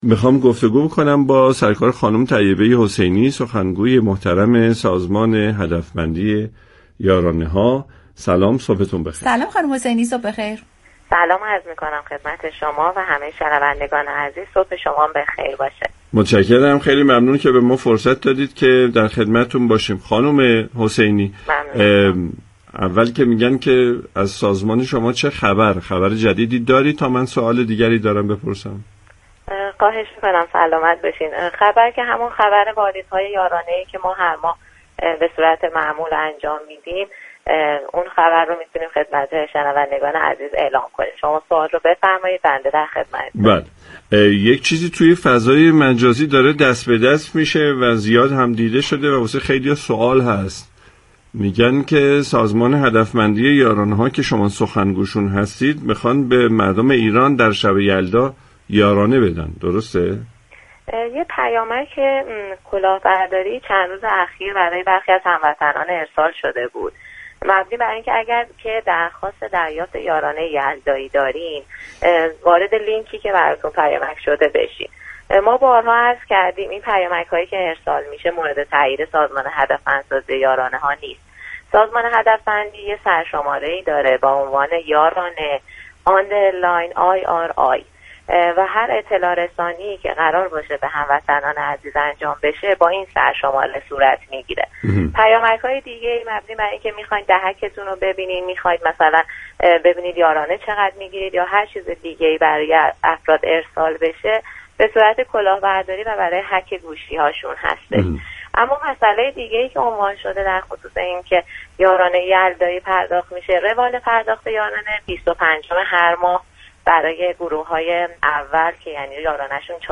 در گفت و گو با «بام تهران»